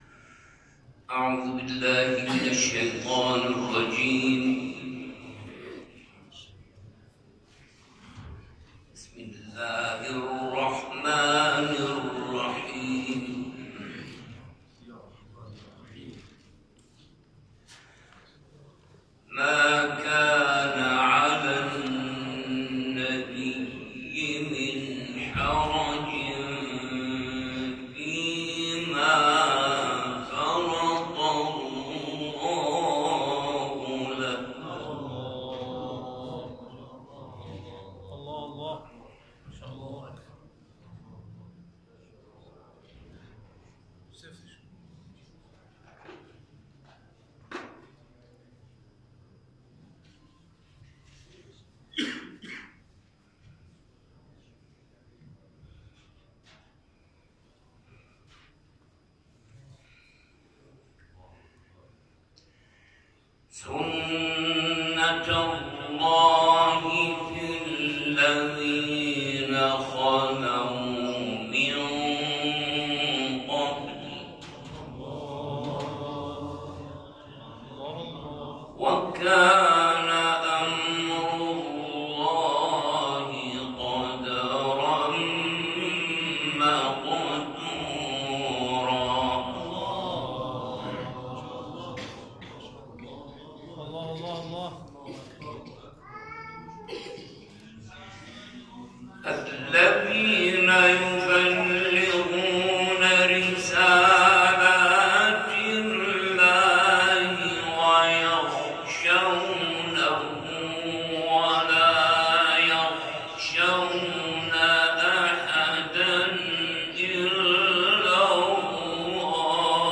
در حرم رضوی
گروه فعالیت‌های قرآنی